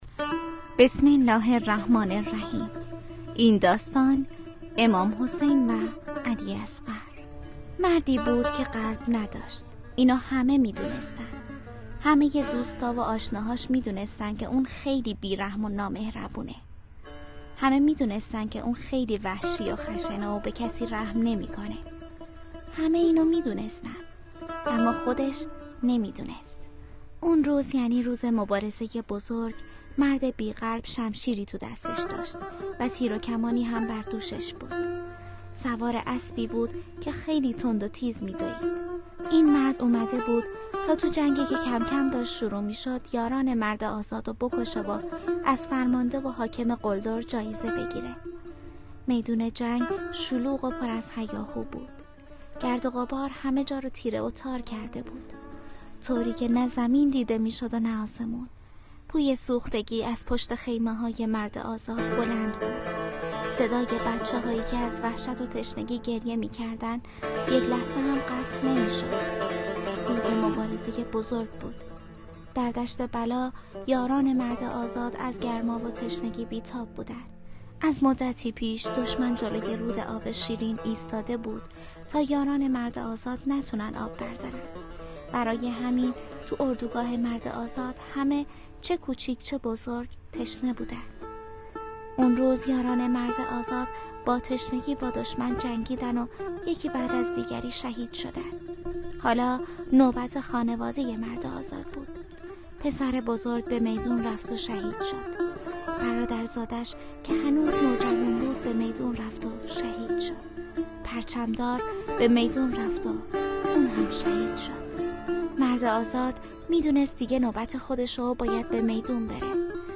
داستان صوتی شهر فراموشی